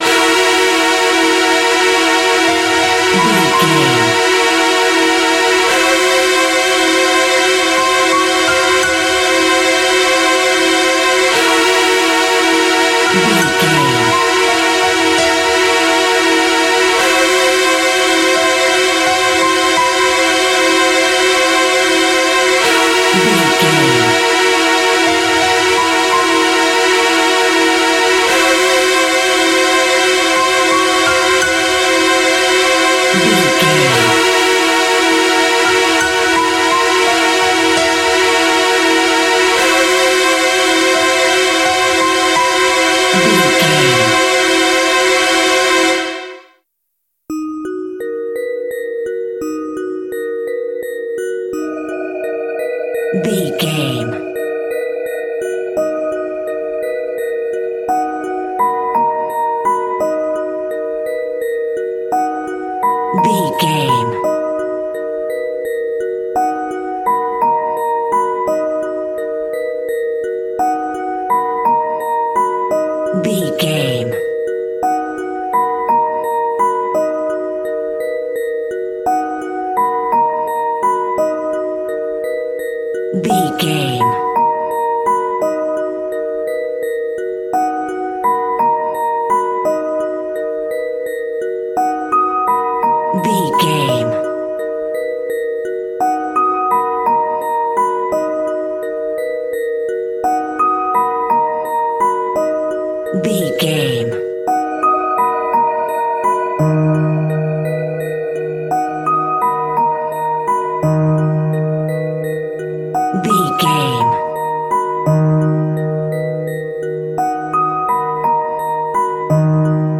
In-crescendo
Aeolian/Minor
ominous
dark
haunting
eerie
horror
creepy
Horror Synths
horror piano
Scary Strings